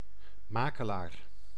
Ääntäminen
France: IPA: [ʁə.pʁe.zɑ̃.tɑ̃]